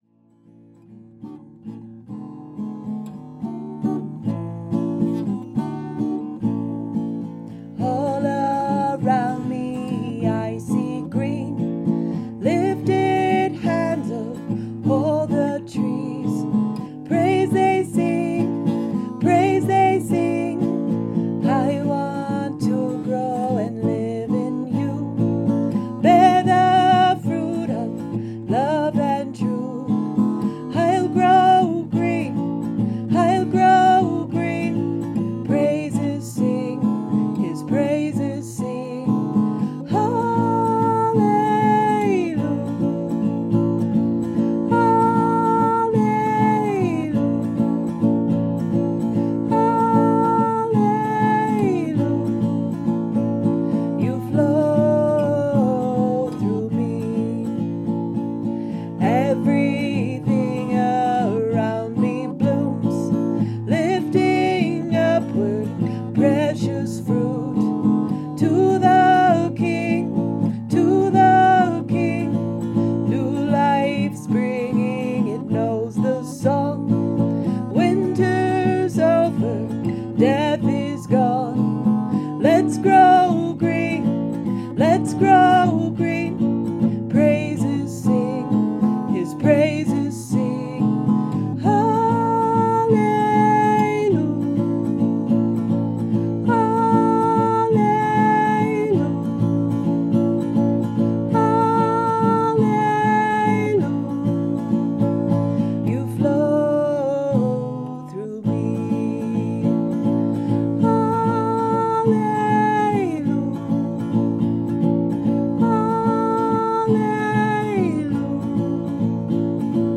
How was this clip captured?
We have sung the chorus two different ways.